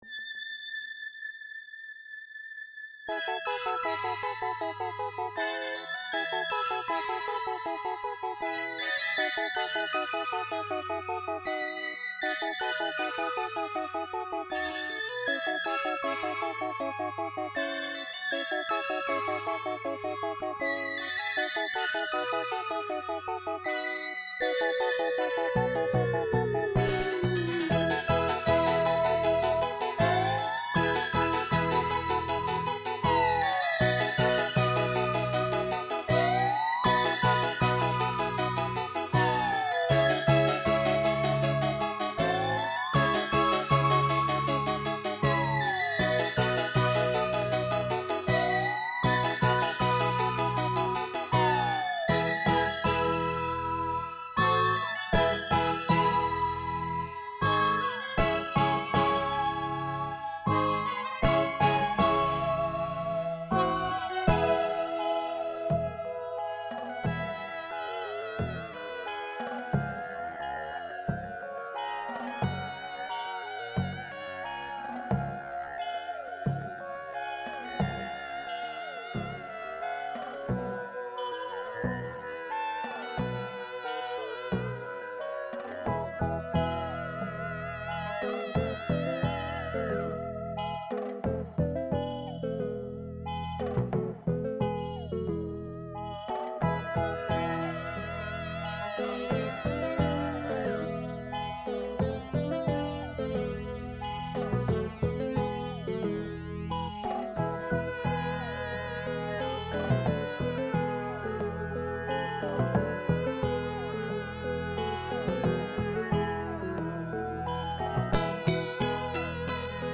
Ambisonic mix
electronic compositions
Ambisonic DTS Source type : Composition Ambisonic order : H (3 ch) 1st order horizontal Creative Commons, Share alike Copyright © Ambisonia 2015